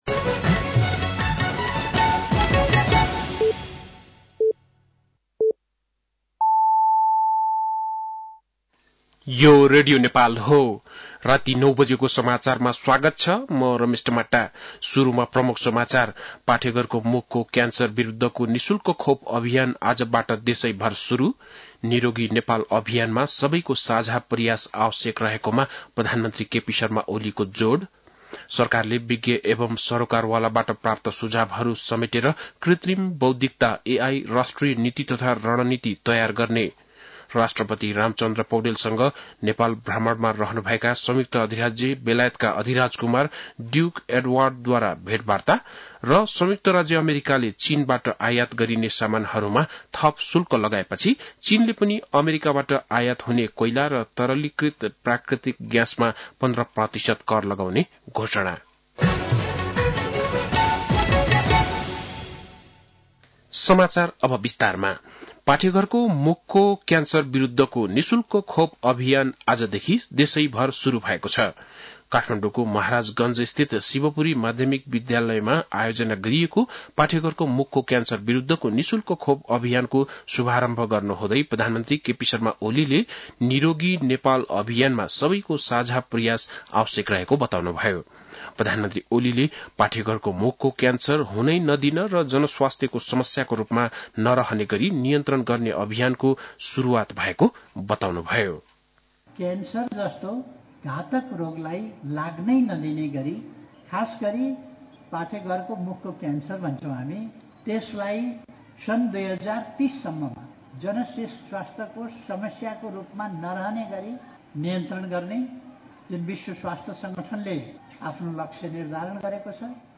बेलुकी ९ बजेको नेपाली समाचार : २३ माघ , २०८१
9-PM-Nepali-News-10-22.mp3